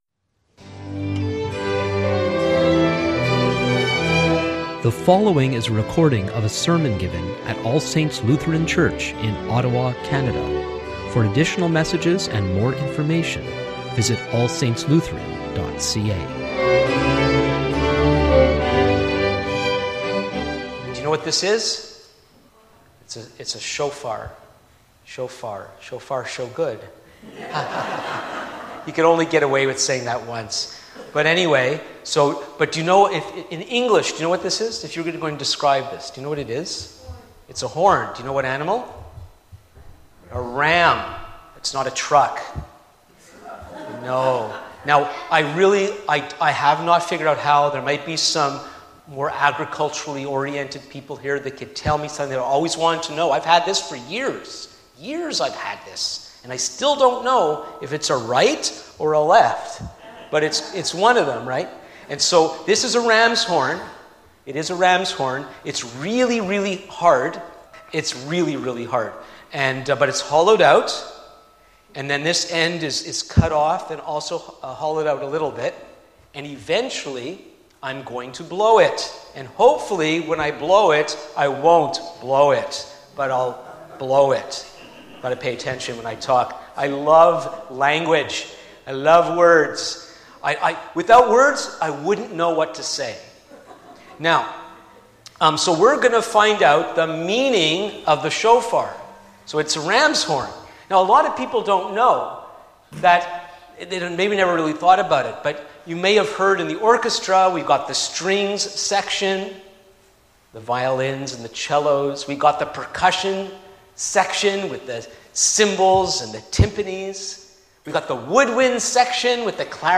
Sermons | All Saints Lutheran Church